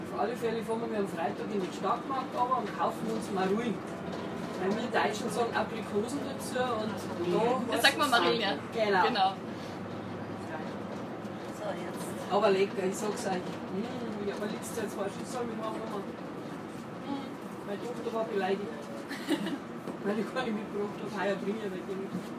Es handelt sich dabei um eine Touristengruppe aus Deutschland.
Von allen Seiten wird gequatscht, sodass ich ihn kaum verstehen kann.
Bevor ich ihr antworten kann, mischt sich die zweite Touristin ein: „Auf jeden Fall kauf’ ich mir am Freitag Maruien.“ Nach einem kurzen Blick in mein verwirrtes Gesicht, fügt sie hinzu: „Wir Deutschen sagen da Aprikosen dazu.“